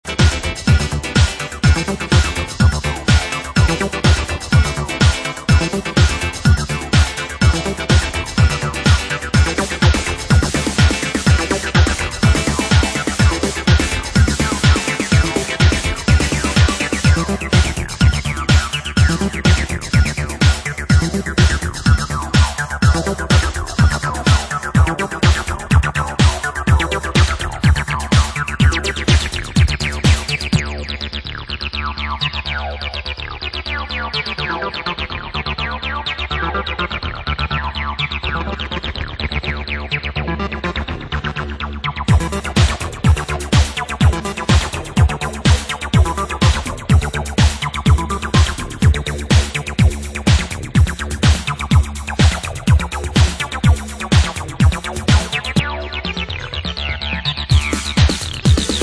】UKメイドのアシッド・ハウスや初期ハウスをコンパイルした強力コンピレーション！